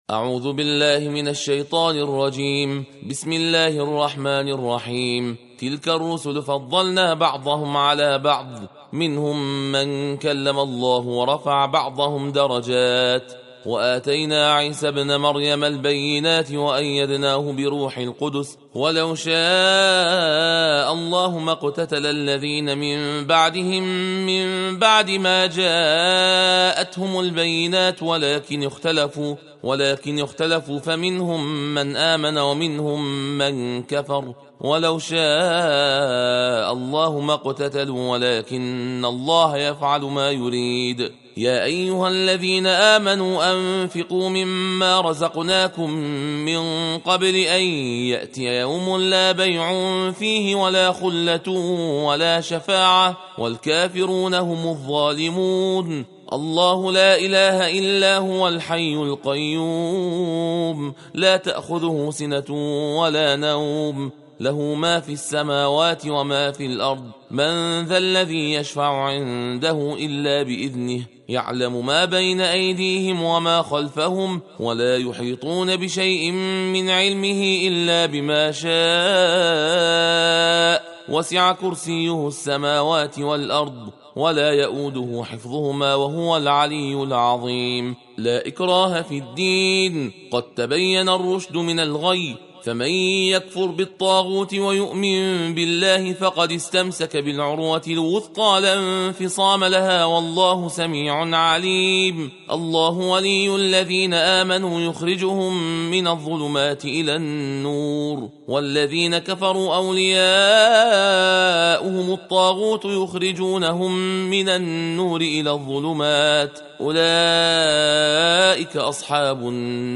تندخوانی(تحدیر) جزء سوم قرآن کریم